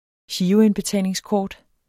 Udtale [ ˈɕiːʁoenbeˌtæˀleŋsˌkɒːd ]